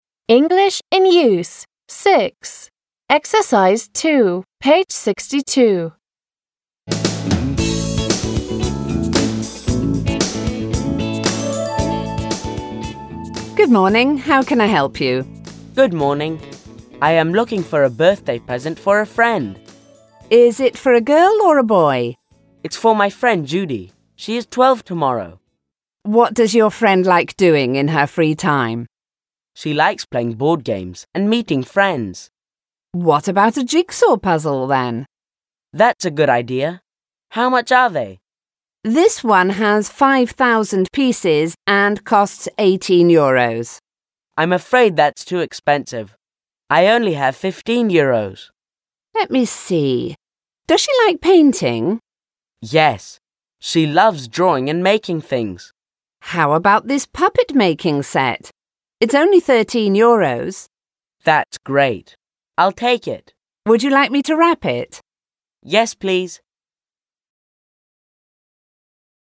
3. Read the dialogue.